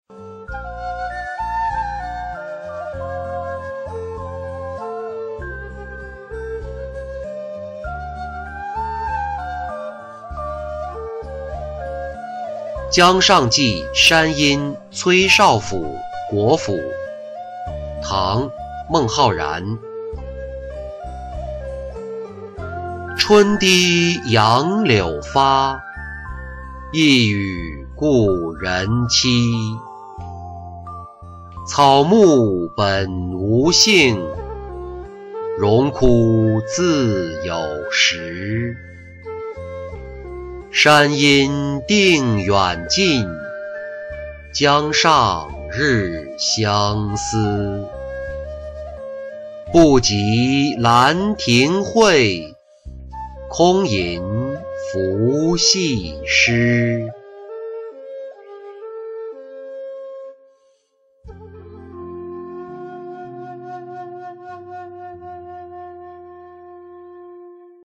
江上寄山阴崔少府国辅-音频朗读